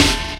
Snare_2_(Cymbal_Steppa).wav